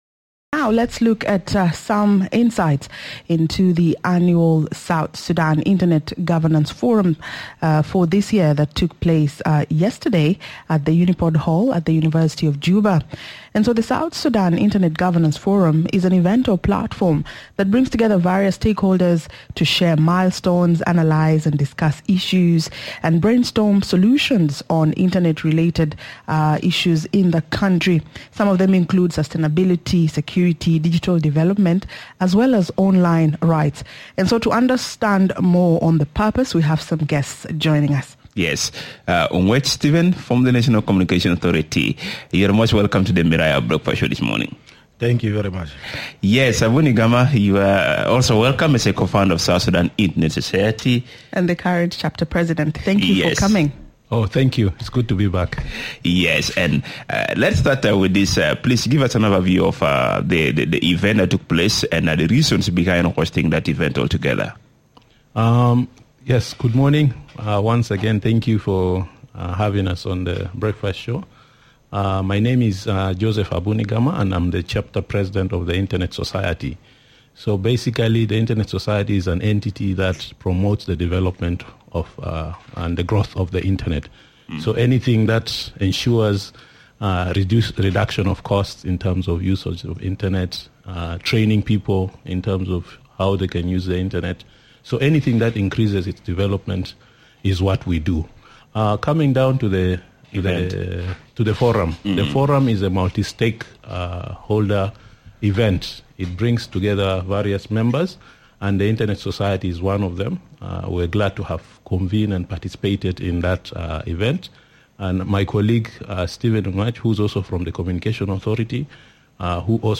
are in conversation with the Miraya Breakfast Show on the just ended Internet Governance Forum.